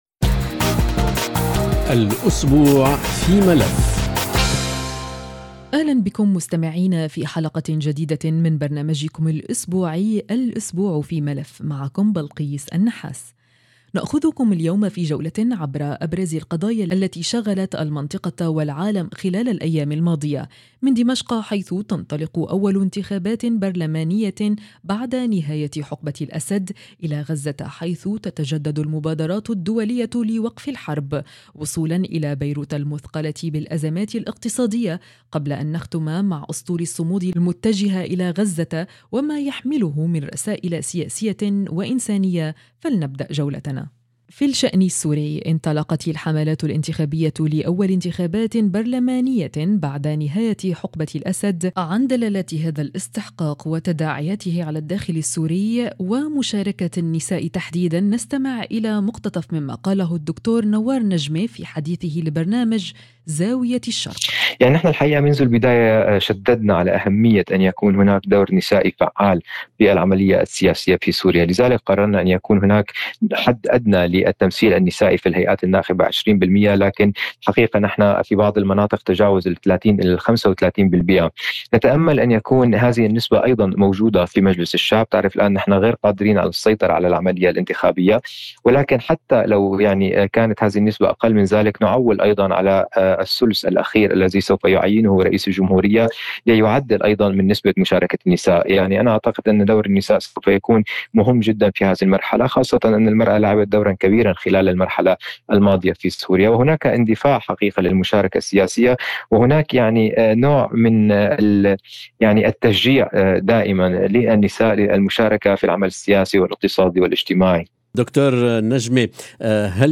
حول التباين في المواقف الدولية بين الادانة والتحفظ بخصوص اعتراض اسرائيل لاسطول الصمود متعدد الجنسيات، نستمع إلى مقتطف مما قاله وزير العدل الفلسطيني السابق وأستاذ القانون الدولي محمد الشلالدة.